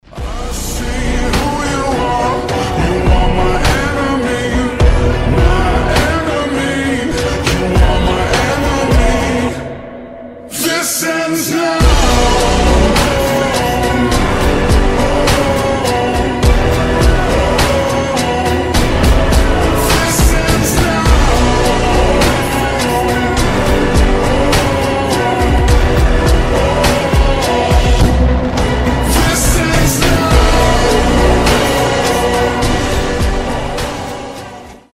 • Качество: 320, Stereo
эпичные
ремиксы